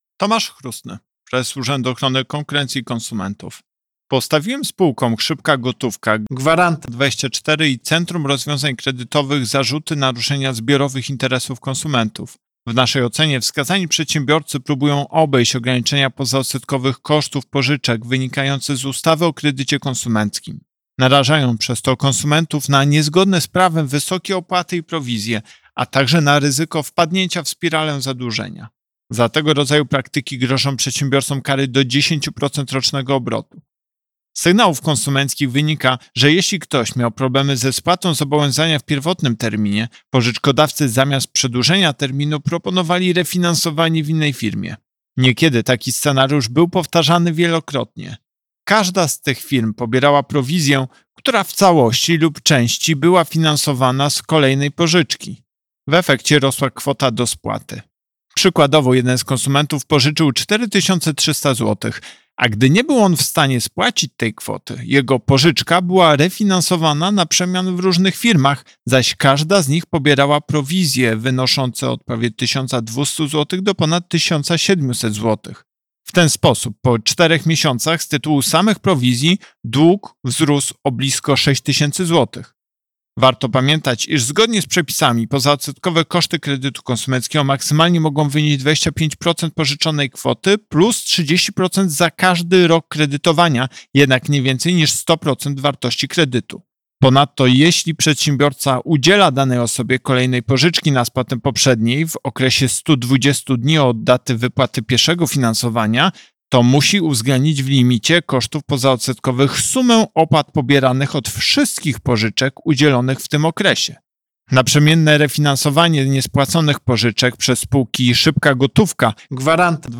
Wypowiedź Prezesa UOKiK Tomasza Chróstnego z 9 sierpnia 2021 r..mp3